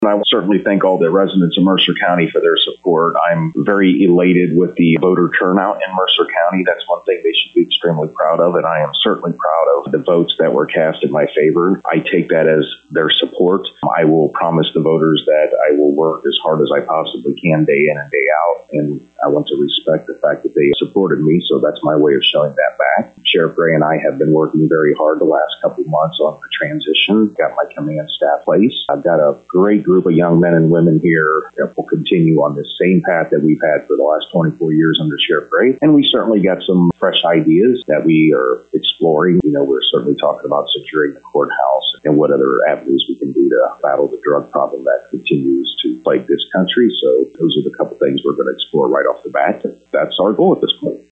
Timmerman, who ran unopposed, thanks the public for their support: